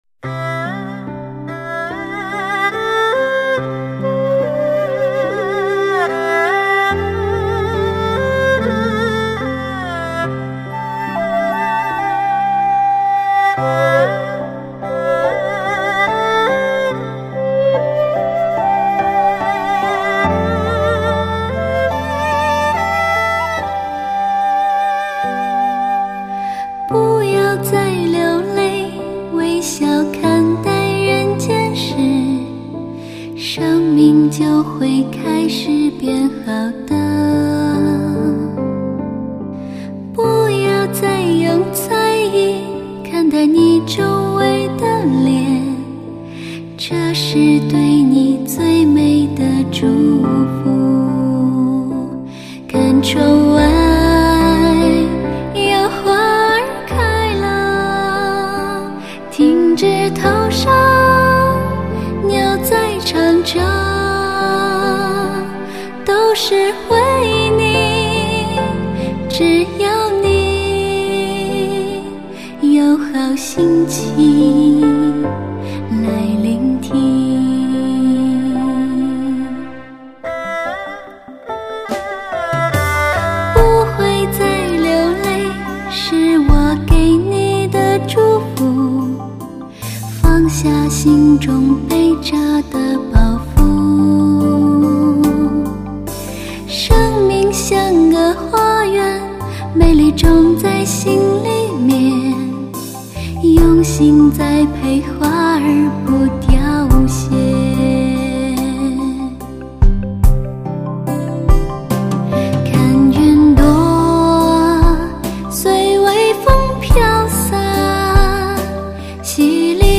最动听的佛教音乐，福佑尘世凡人，
水晶般纯净的女声，造福众生的佛颂，
清幽抒情的旋律，时尚清闲的编配，